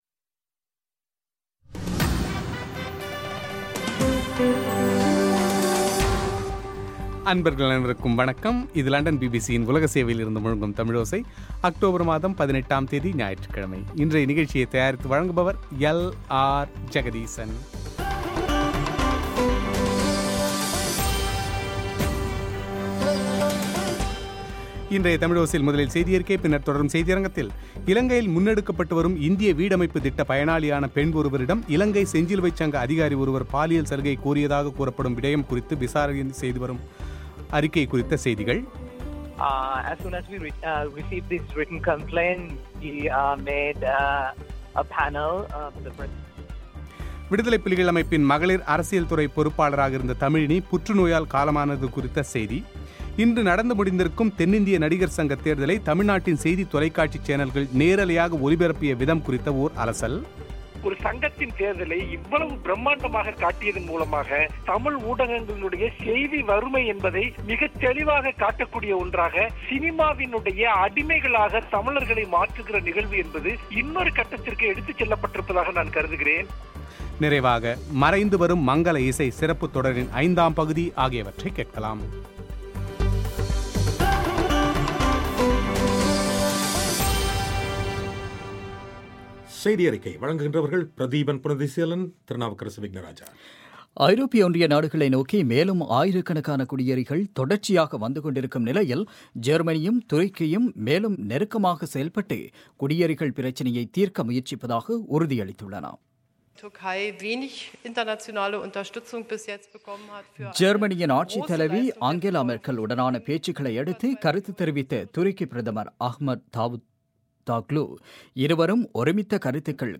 இன்று நடந்துமுடிந்துள்ள தென்னிந்திய நடிகர் சங்கத் தேர்தல் குறித்த செய்திகளை தமிழ்நாட்டின் செய்தித் தொலைக்காட்சி சேனல்கள் கடந்த பலநாட்களாக விரிவாக அலசியதுடன் இன்று ஒட்டுமொத்த தேர்தலையும் நாள் முழுக்க நேரலையாக ஒளிபரப்பிய விதம் சமூக வலைத்தளங்களில் பெரும் விமர்சனத்துக்குள்ளாகி வரும் பின்னணியில் இந்த தேர்தலை தமிழக செய்தித் தொலைக்காட்சிகள் கையாண்ட விதம் சரியா என்பது குறித்து எழுத்தாளரும் விமர்சகருமான மனுஷ்யபுத்திரனின் செவ்வி;